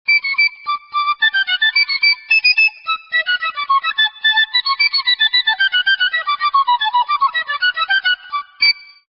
Le Picolo ou petite fl�te
Présentation: La flûte piccolo ou petite flûte est deux fois plus petite que la flûte et sonne à l'octave aigu de celle-ci.
Pour les deux instruments, le son est produit par un filet d'air qui est dirigé sur l'arête de l'embouchure et fait vibrer la colonne d'air du tuyau.
Extrait Piccolo solo
piccolo.mp3